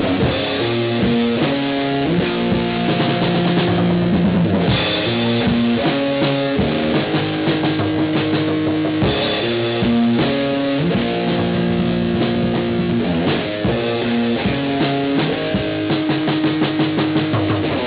He could keep a rock solid beat though.